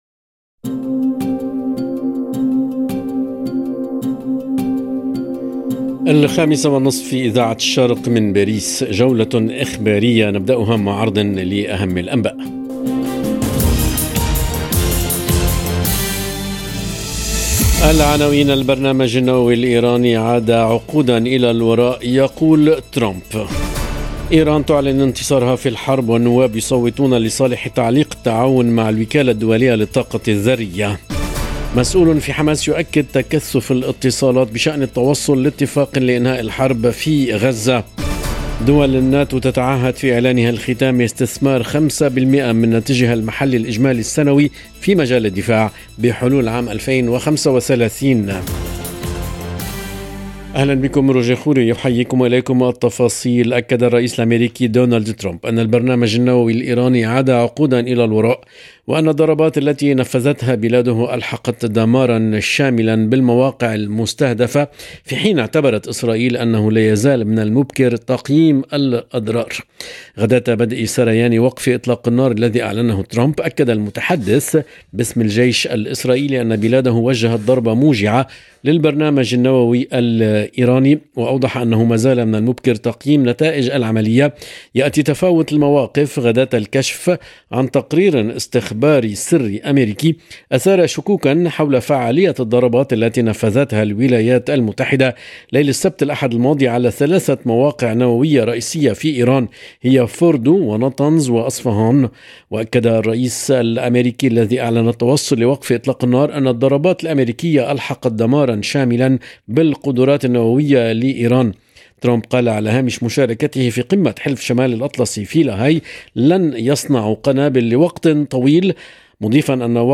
نشرة أخبار المساء: وفقاً لترامب البرنامج النووي الإيراني عاد عقوداً إلى الوراء - Radio ORIENT، إذاعة الشرق من باريس